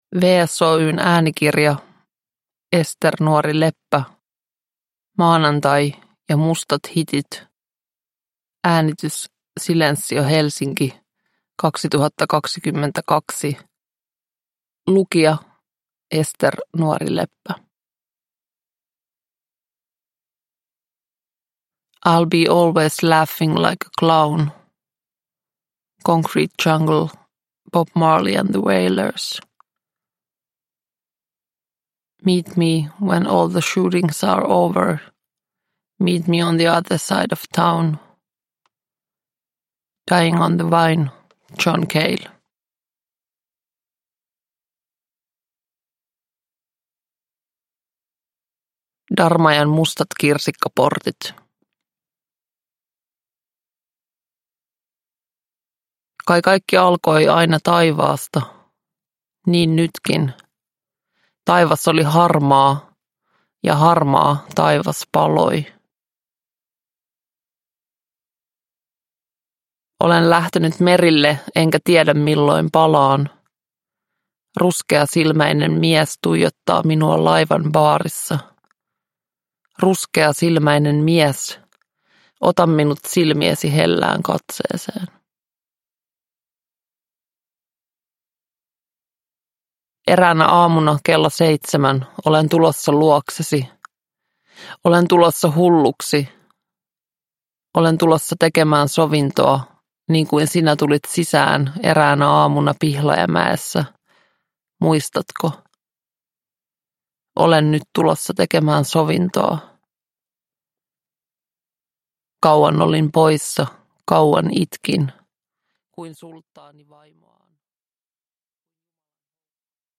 Maanantai ja mustat hitit – Ljudbok – Laddas ner